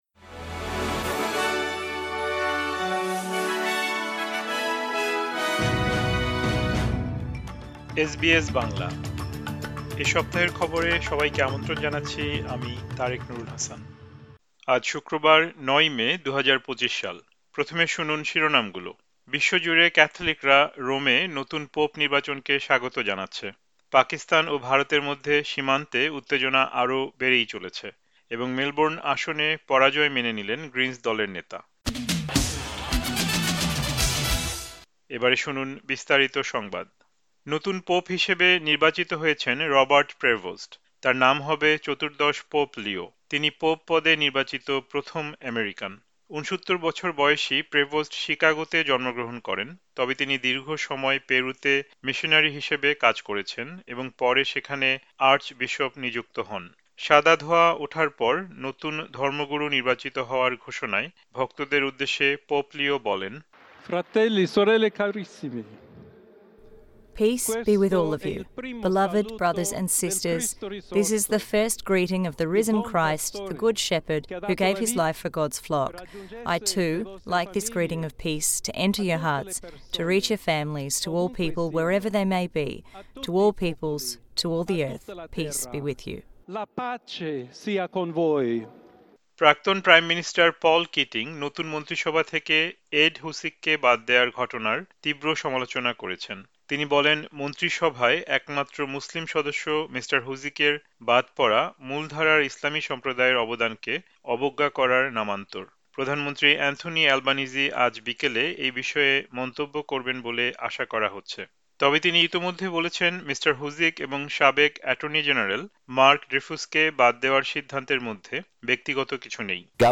এ সপ্তাহের খবর: ৯ মে, ২০২৫